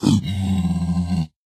zpigangry3.mp3